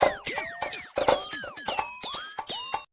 All these loops were created at loopasonic and are all original and copyright free.
ODD SHUFFLE 85 F/X (244Kb)